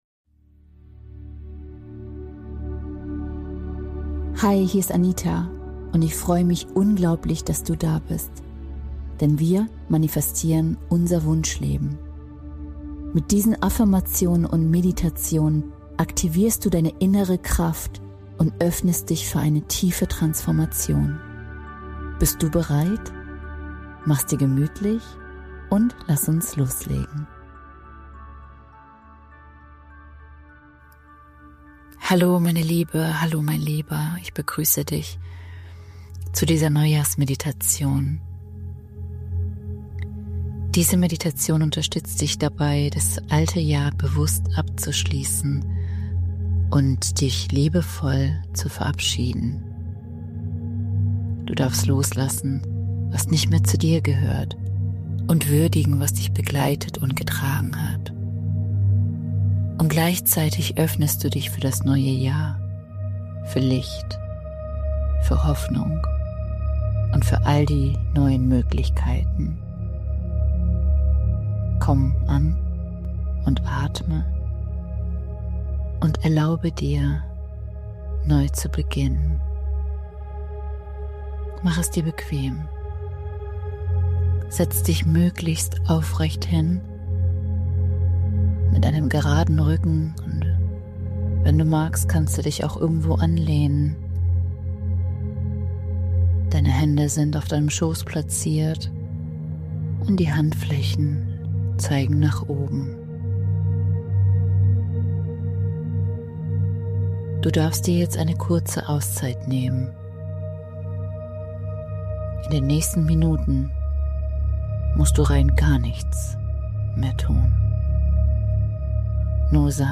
Du wirst sanft in einen Zustand tiefer Entspannung geführt, in dem du das vergangene Jahr würdigen darfst – mit allem, was war.
Eine geführte Visualisierung lädt dich ein, dein neues Jahr mit allen Sinnen zu erkunden und dich mit der Qualität zu verbinden, die dich begleiten darf. Kraftvolle Affirmationen unterstützen dich dabei, Vertrauen, Klarheit und innere Ausrichtung zu verankern.